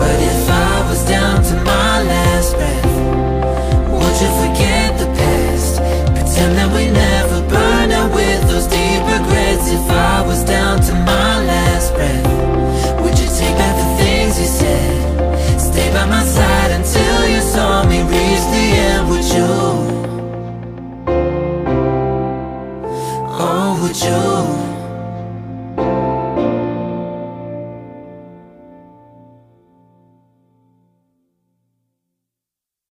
amazing song 😍🎤